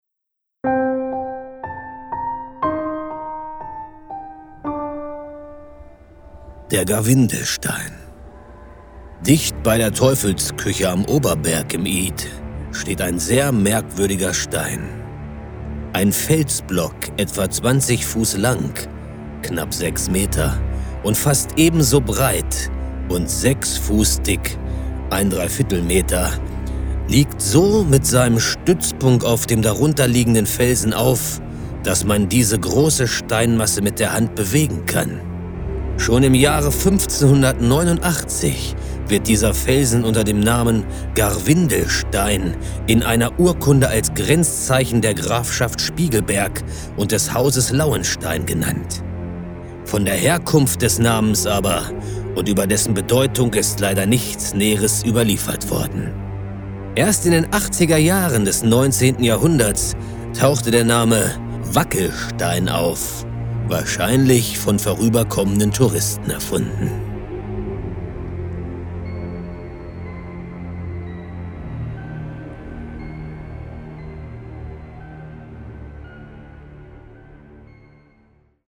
Ein spannendes Hörspiel rund um den Garwindelstein im Ith bei Coppenbrügge.